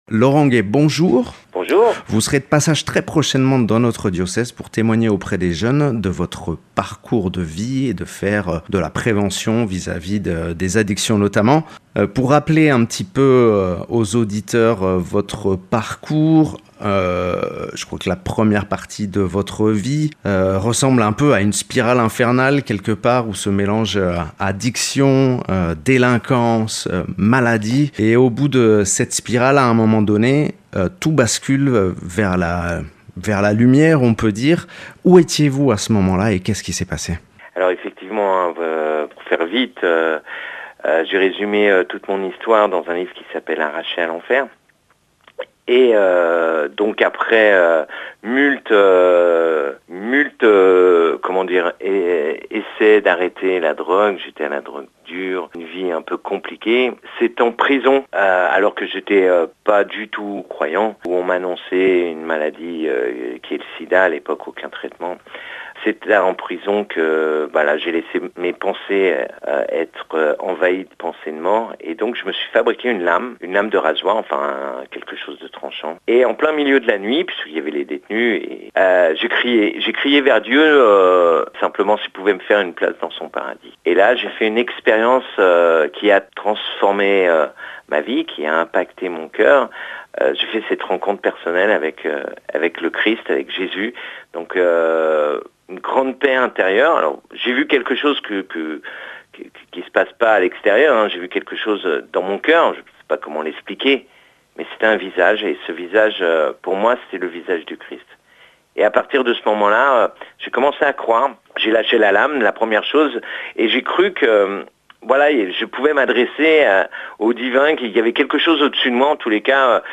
Accueil \ Emissions \ Infos \ Interviews et reportages \ Troubadour d’espérance